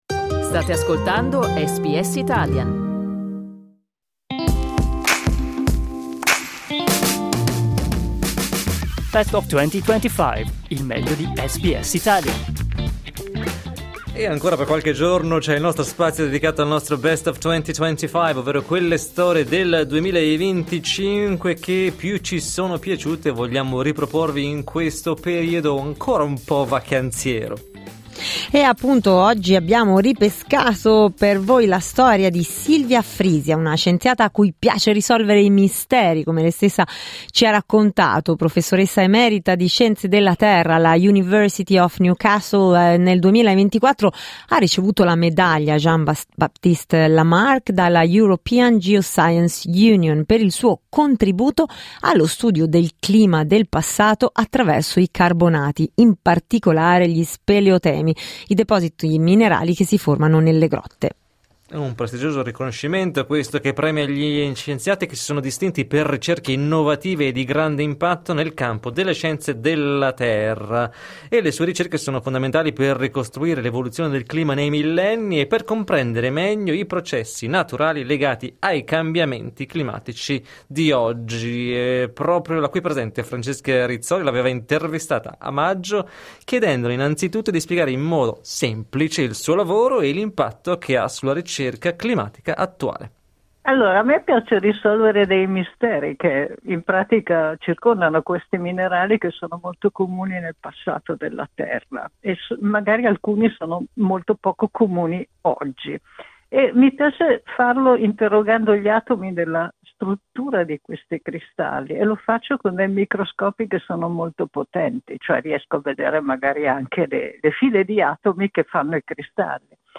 Clicca sul tasto "play" in alto per ascoltare l'intervista Il suo contributo fondamentale riguarda lo studio dei carbonati, in particolare gli speleotemi, i depositi minerali che si formano nelle grotte.